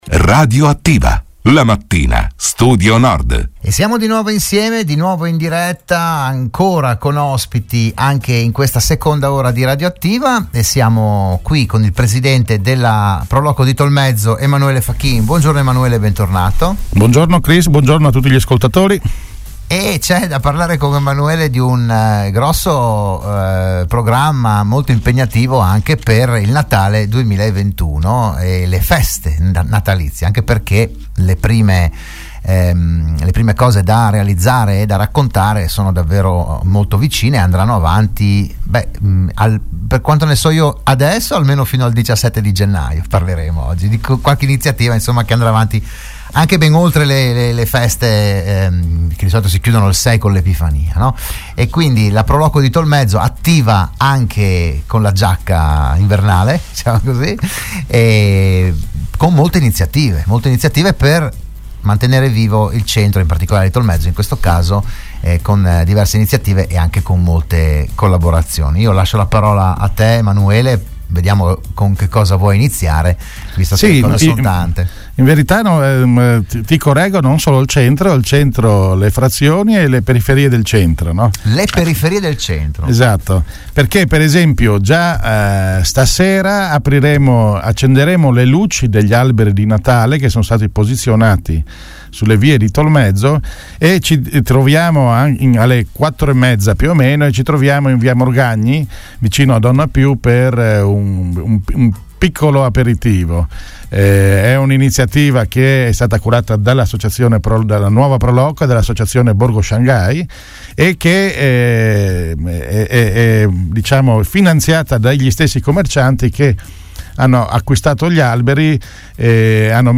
L'AUDIO dell'intervento a Radio Studio Nord